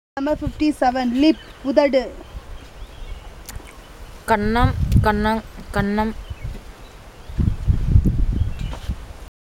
Elicitation of words about human body parts - Part 15